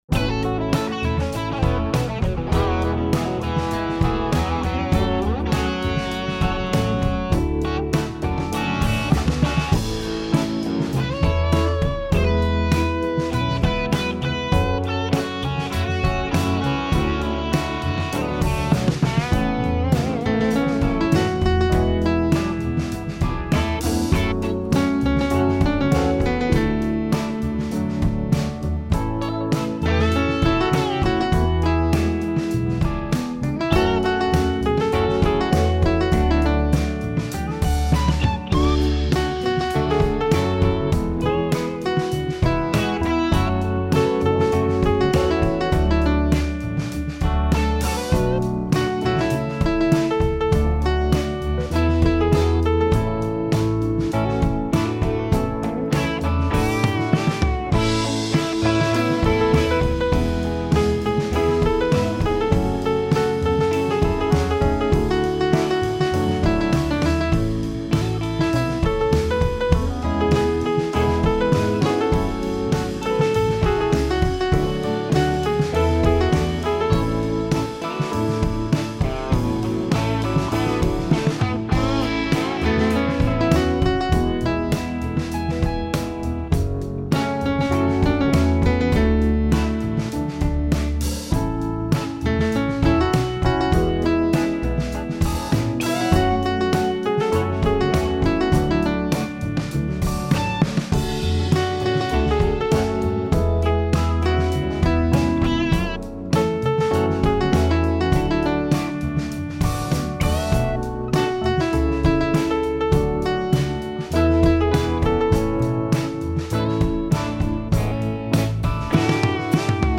This is a super syncopated P&W song